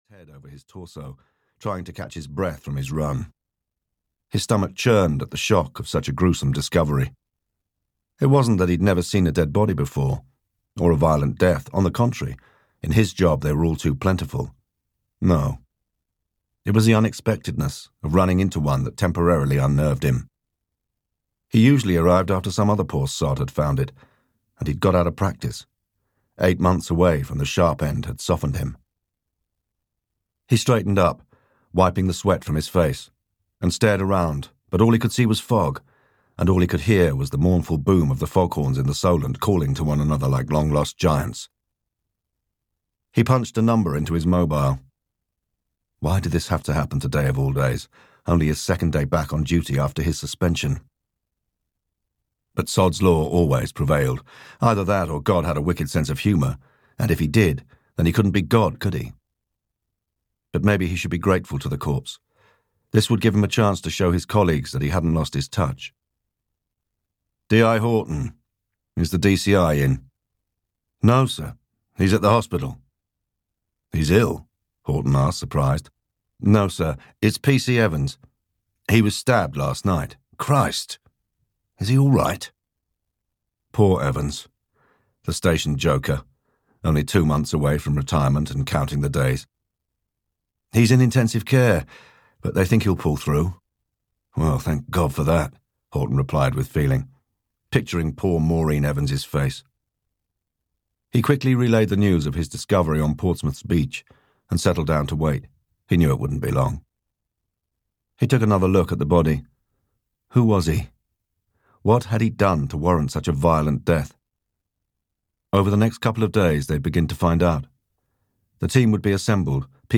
The Portsmouth Murders (EN) audiokniha
Ukázka z knihy